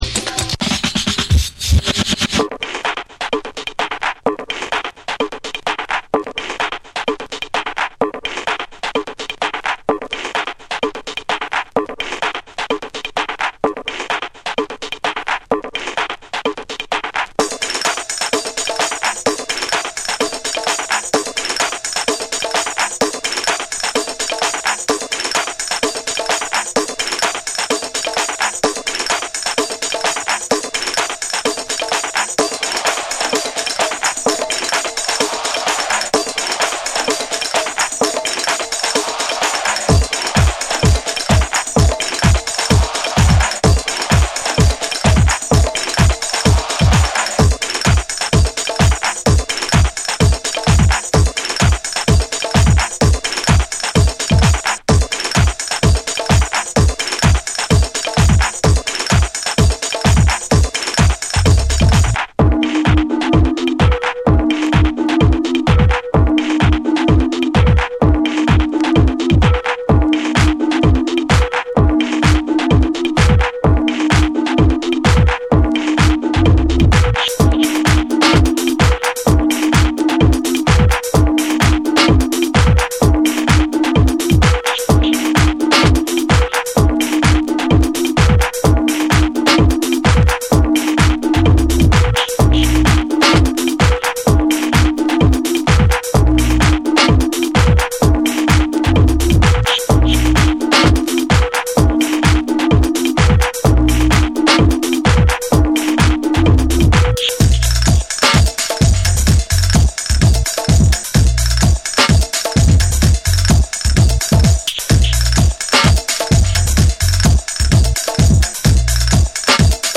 変則的なリズムと幻想的なシンセが絡み合うダビーでトライバルなディープ・ハウス
TECHNO & HOUSE